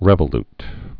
(rĕvə-lt)